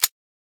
grenadepull.ogg